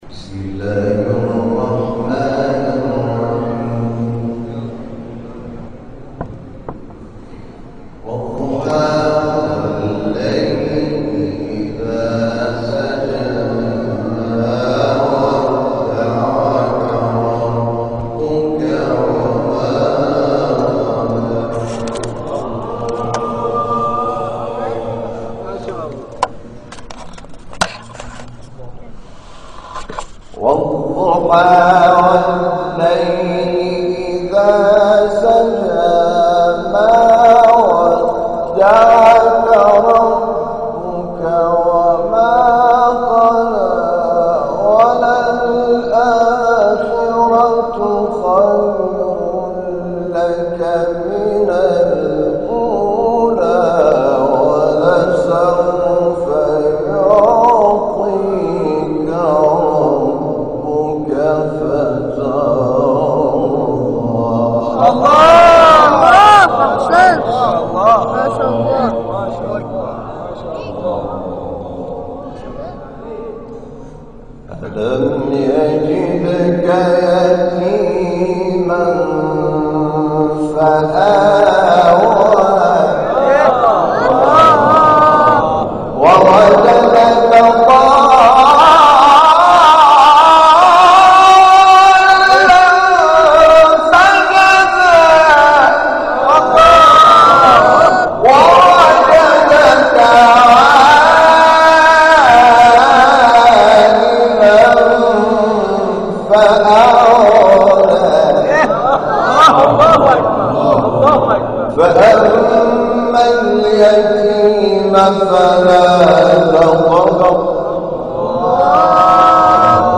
جدیدترین تلاوت حامد شاکرنژاد
گروه جلسات و محافل: جلسه قرآن حامد شاکرنژاد، مدرس و قاری کشورمان در مسجد جامع آل یاسین با حضور قرآن‌آموزان از نقاط مختلف کشور برگزار شد و حسن ختام این جلسه تلاوت کوتاه حامد شاکرنژاد بود.
در پایان این جلسه قرآن، حامد شاکرنژاد دقایق کوتاهی به تلاوت آیاتی از سوره های ضحی و انشراح پرداخت که در ادامه ارائه می‌شود.